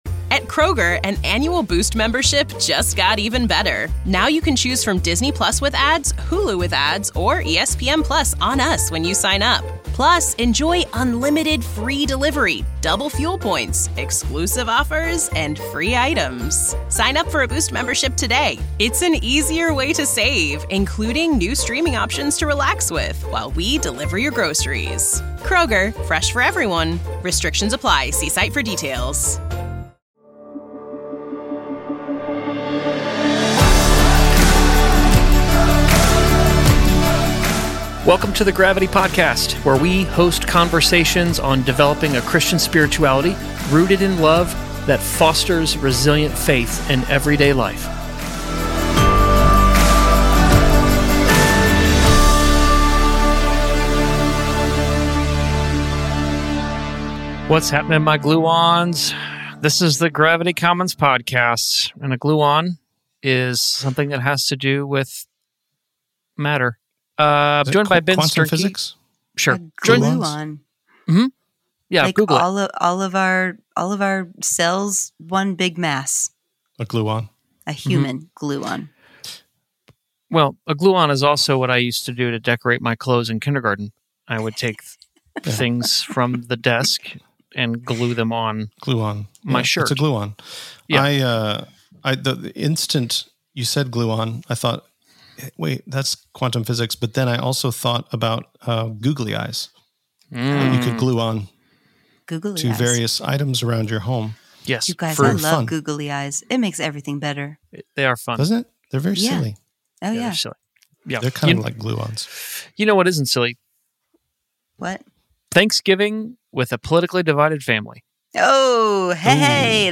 Conversations to help us develop a Christian spirituality rooted in love that fosters resilient faith in everyday life